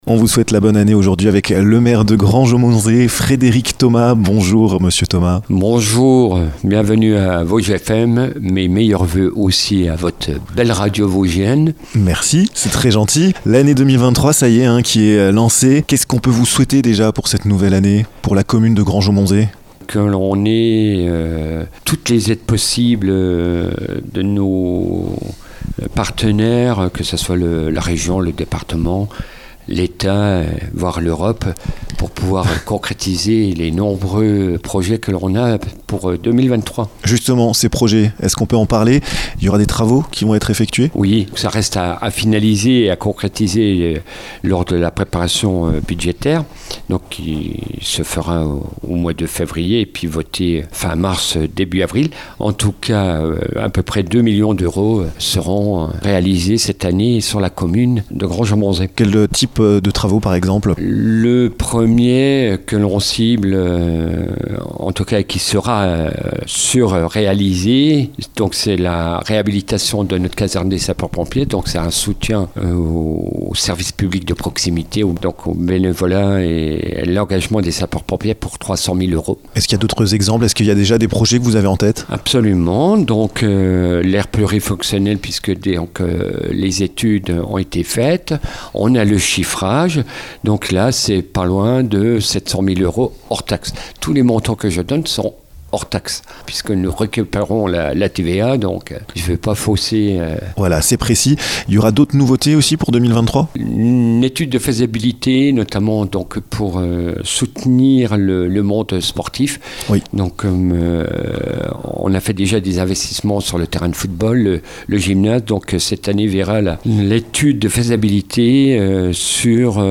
Frédéric Thomas, maire de Granges-Aumontzey, vous présente ses meilleurs voeux pour cette nouvelle année. Projets, travaux, sport, culture, on fait le point dans ce podcast!